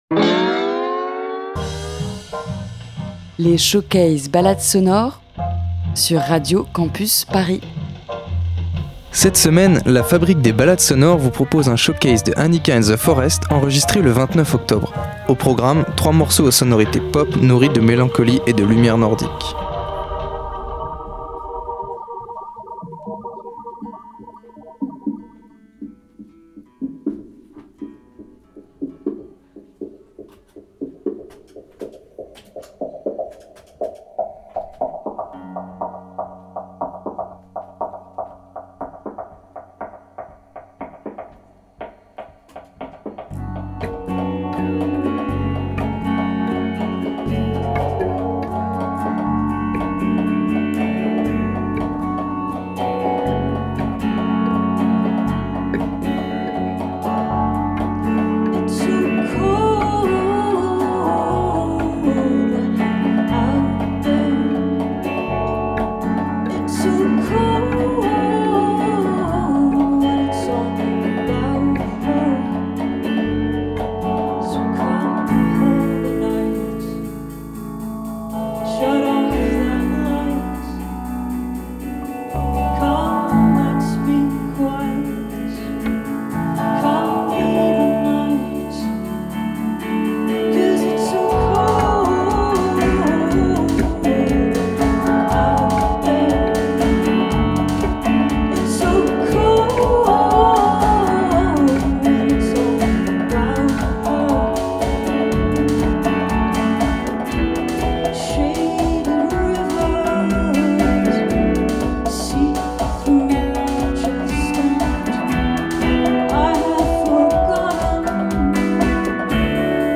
Entre pop et mélancolie aux lumières nordiques